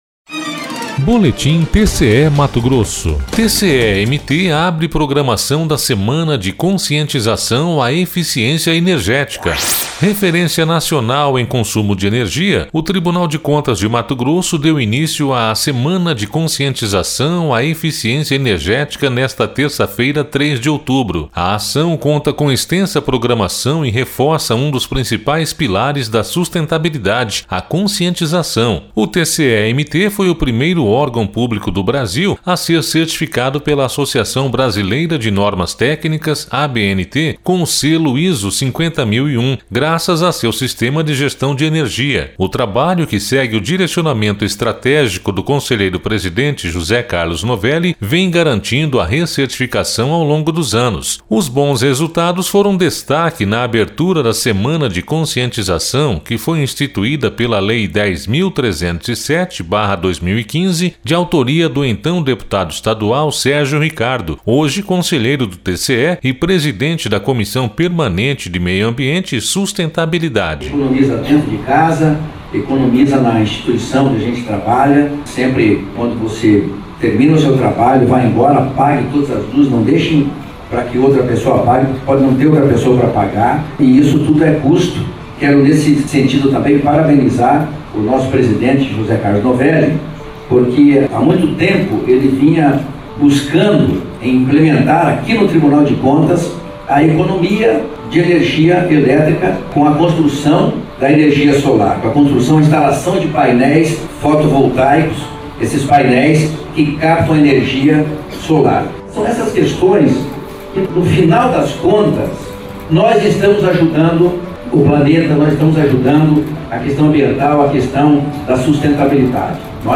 Sonora: Sérgio Ricardo – conselheiro presidente da CPMAS
Sonora: Alisson Carvalho de Alencar - procurador-geral do MPC-MT